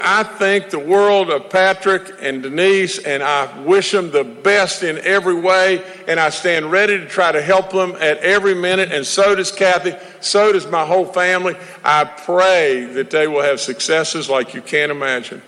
Governor Justice’s Final Address